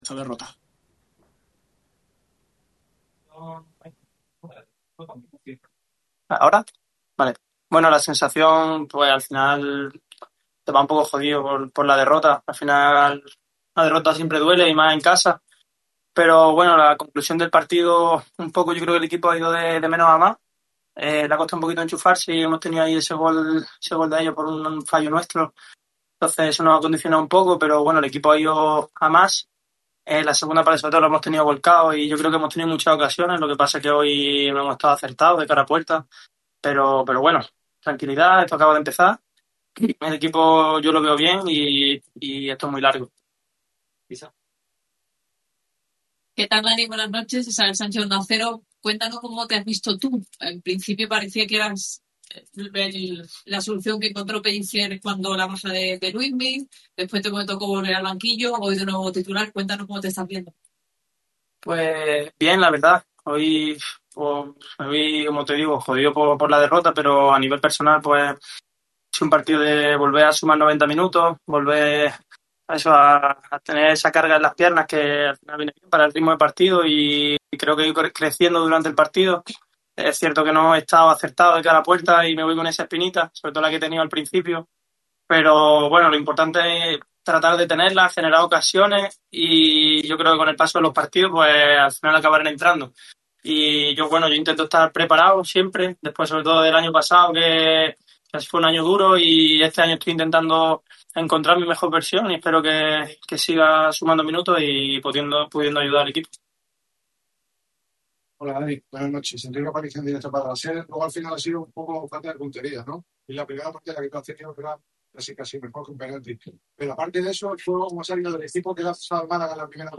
El futbolista del Málaga CF ha comparecido ante los medios de comunicación al término del envite que ha enfrentado a los boquerones contra el Cádiz CF. Los del Nuevo Mirandilla asaltaron La Rosaleda gracias a un solitario tanto de Ortuño. El ’22’ de los locales disputó los 90 minutos de partido y valora la derrota tratando de sacar una visión positiva de la misma.